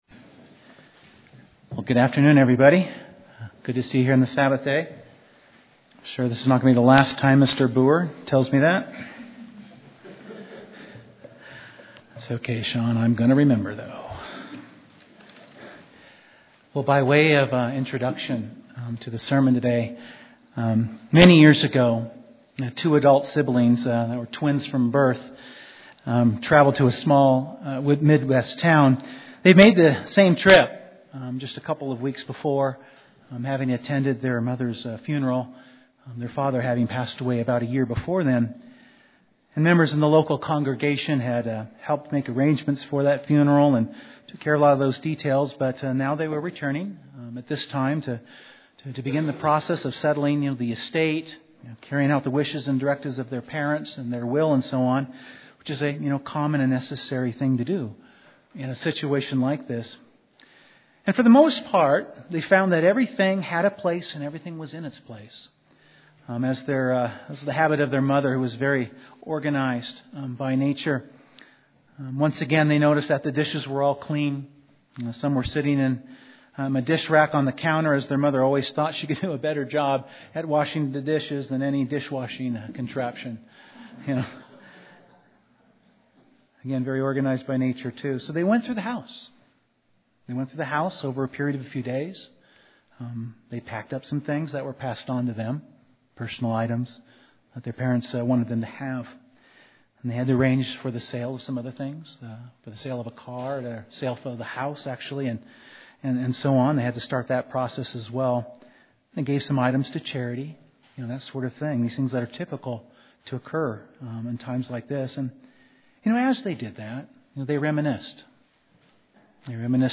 Prayer is an essential part of our relationship with God. This sermon focuses on some of the instructions and guidance God gives regarding prayer with a focus on seeking God’s will (1 John 5:14).